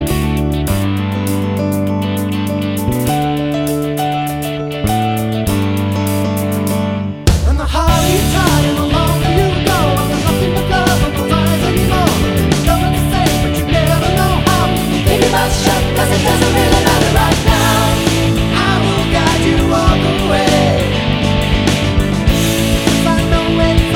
With Girl Rock 7:04 Buy £1.50